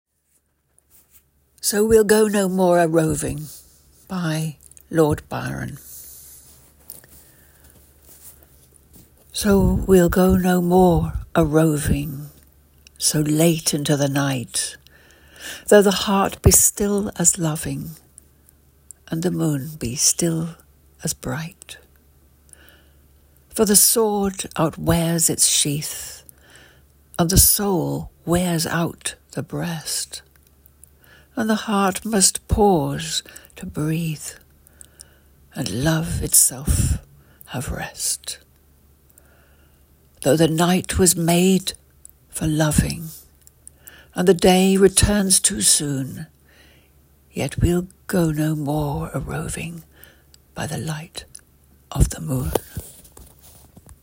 So we’ll go no more a roving by Lord Byron read by Ruth Padel